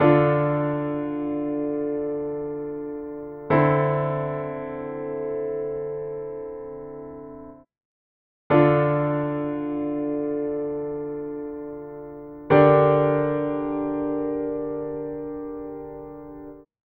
Der song gleich am anfang, beginnt mit den glocken.